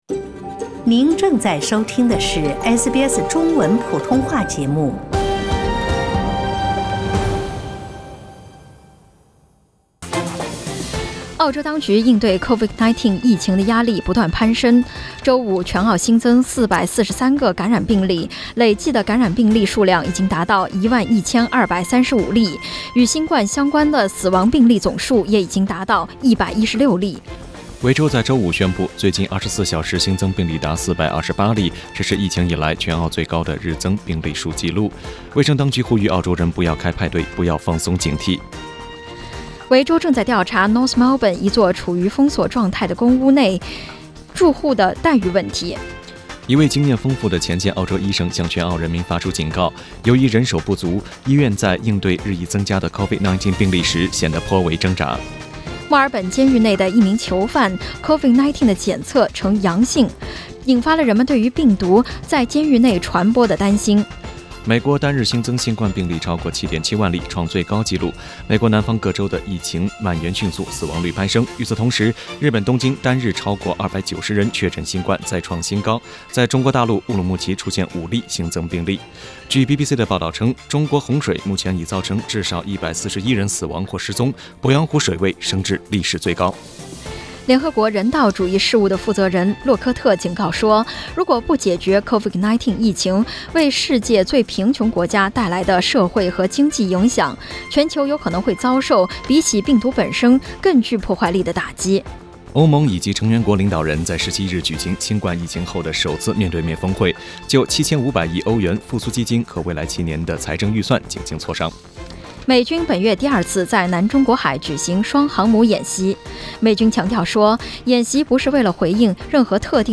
SBS早新闻 (7月18日)